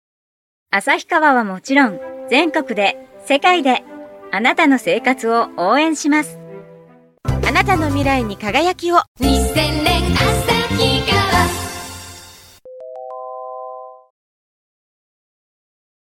街頭放送CMの専門家
音の広告　街頭放送